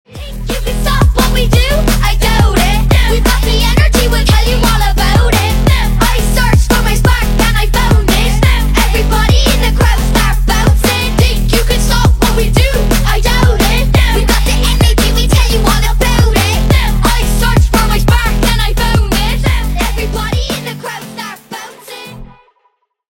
зарубежные веселые